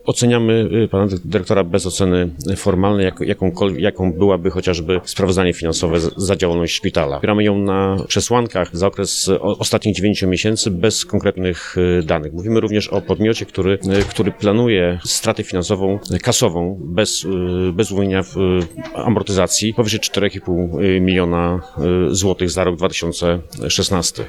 Stargardzki szpital gorącym tematem XXIII sesji Rady Powiatu
– mówi przewodniczący komisji budżetu – Sławomir Rutkowski.
rutkowski odpowiada.mp3